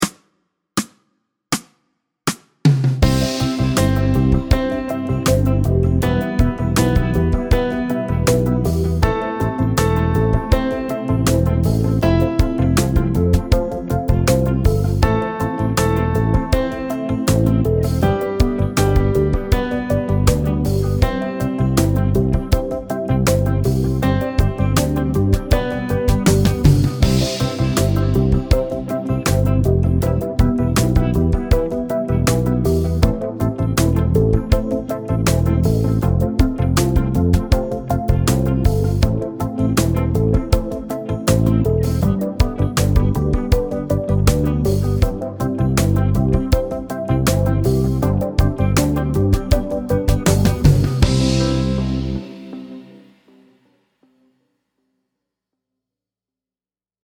Fast C instr (demo)